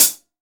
hat semicl2.WAV